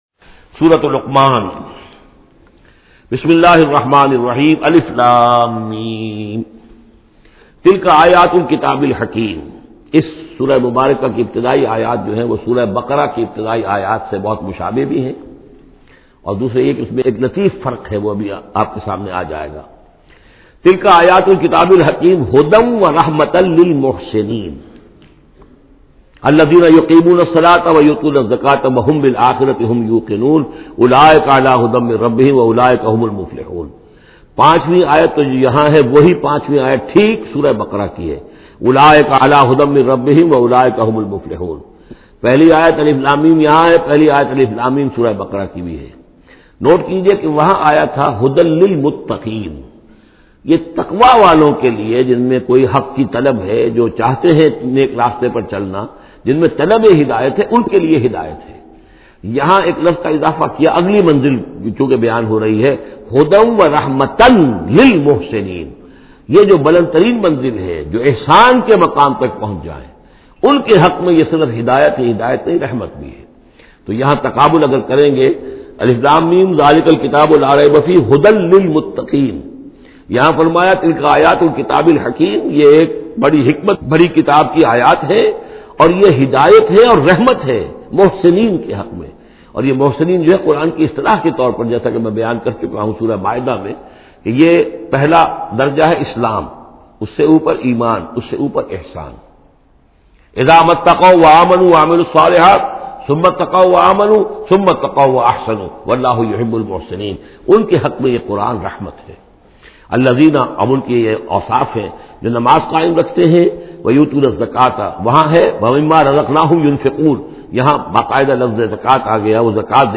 Surah Luqman Urdu Tafseer by Dr Israr Ahmed